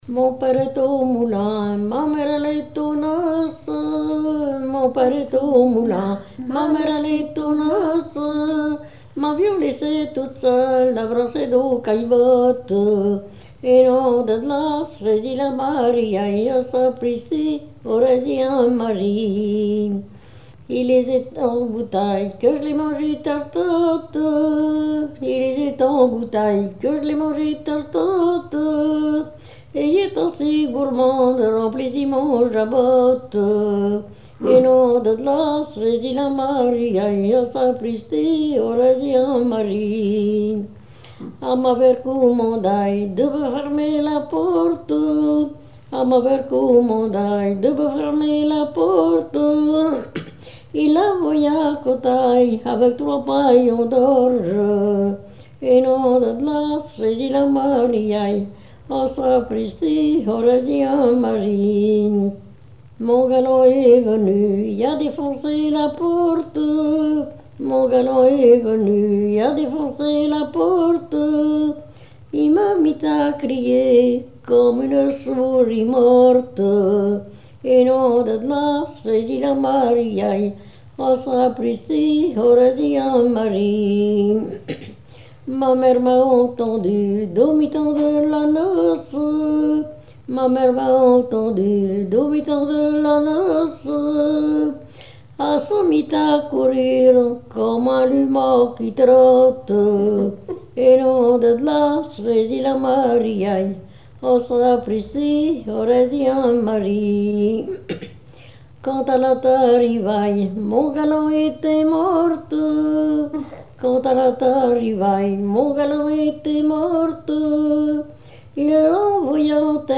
Genre laisse
Témoignages et chansons
Pièce musicale inédite